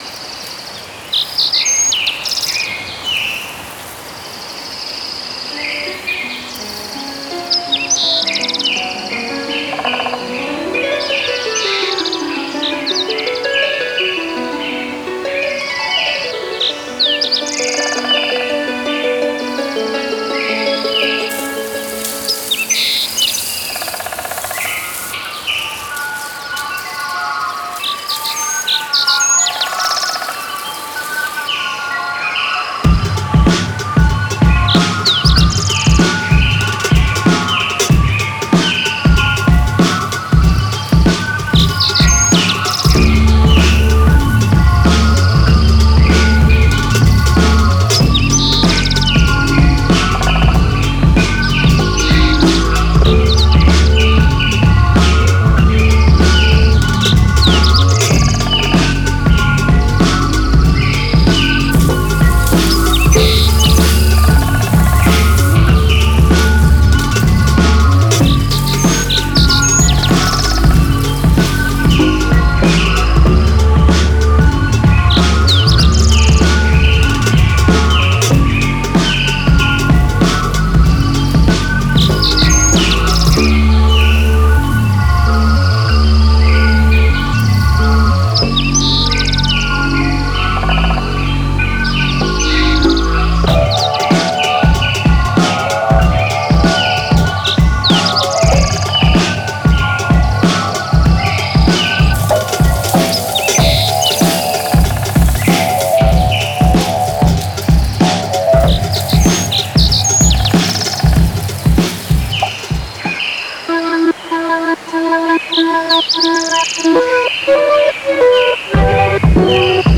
Greek composer